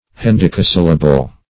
Search Result for " hendecasyllable" : The Collaborative International Dictionary of English v.0.48: Hendecasyllable \Hen*dec"a*syl`la*ble\, n. [L. hendecasyllabus, Gr.